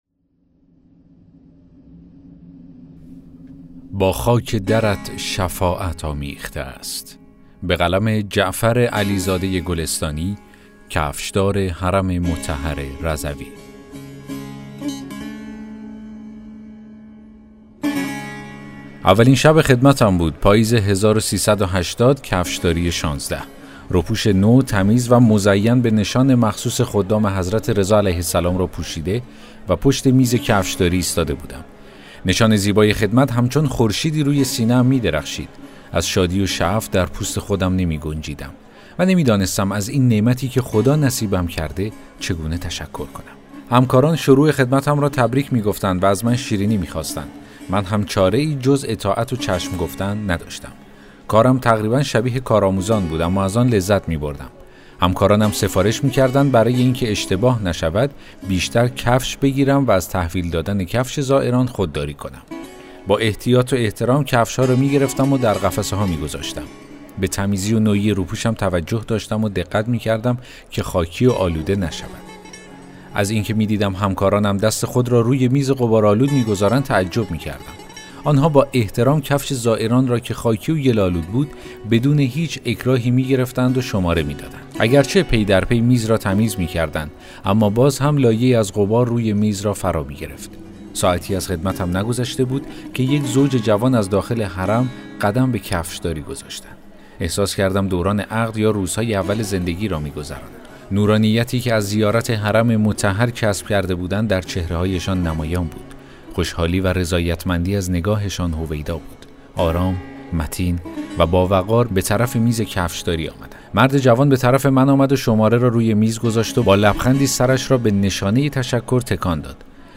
داستان صوتی: با خاک درت شفاعت آمیخته است